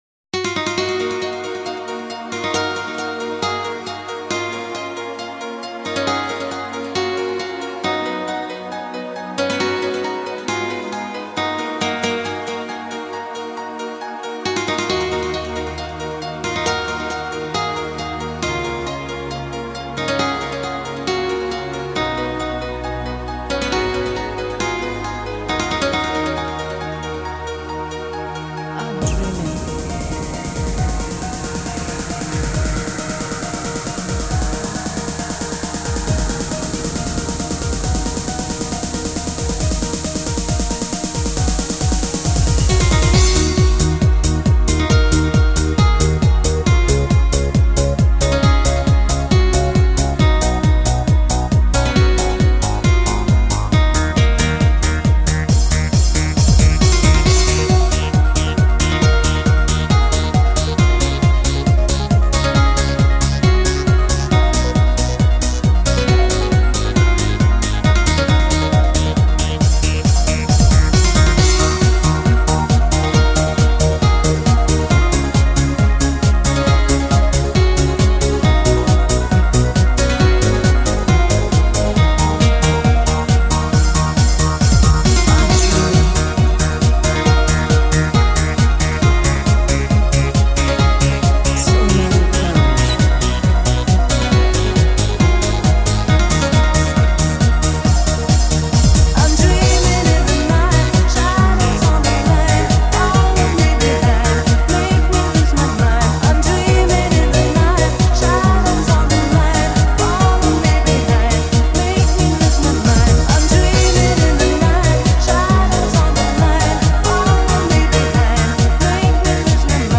Жанр: dance